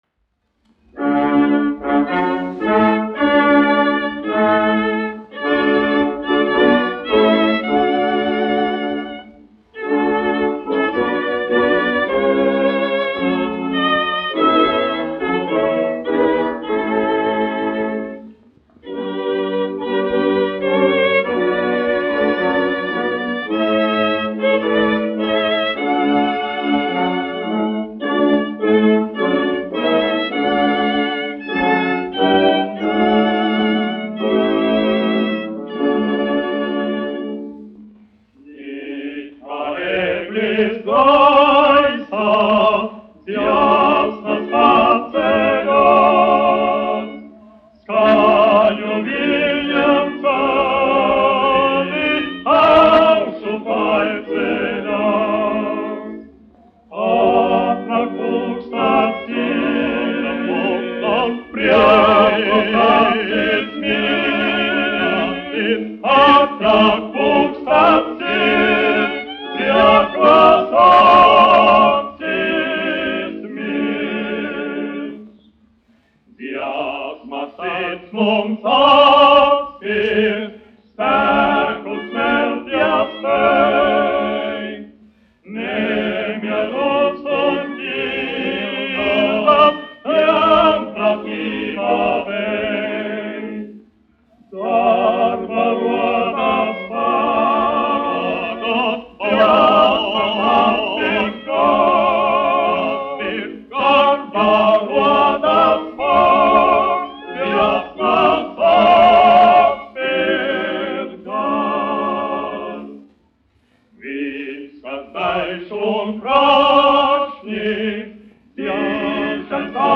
1 skpl. : analogs, 78 apgr/min, mono ; 25 cm
Vokālie kvarteti ar orķestri
Dziesmas ar orķestri
Skaņuplate